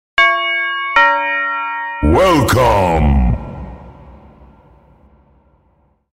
Ding-Dong Monster Welcome Sound Effect
A classic ding-dong doorbell followed by a deep, spooky monster voice saying “Welcome” with reverb. Perfect for Halloween, haunted houses, horror games, or spooky parties.
Ding-dong-monster-welcome-sound-effect.mp3